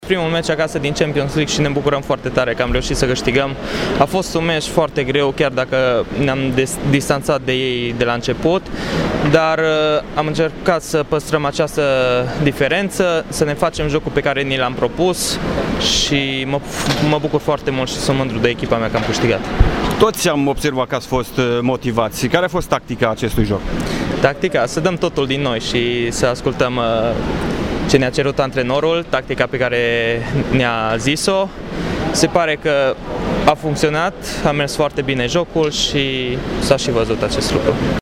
Doi dintre jucătorii învingători au vorbit despre reușita din bazinul Ioan Alexandrescu.